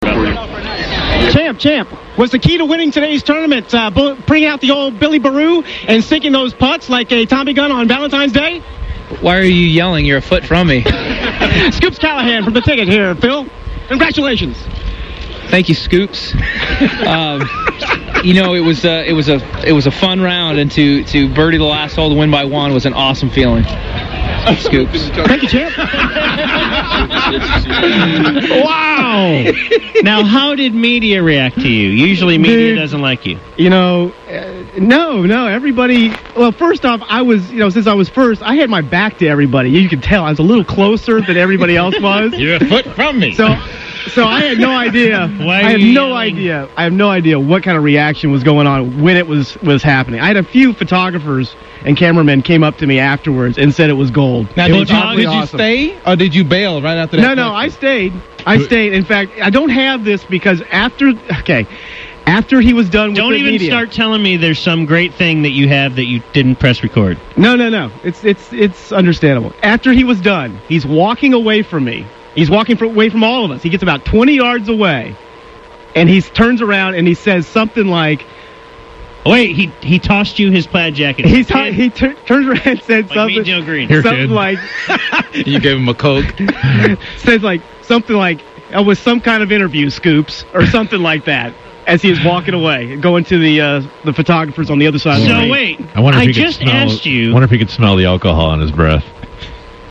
1920’s Reporter (aka Scoops) Interviews Phil Mickelson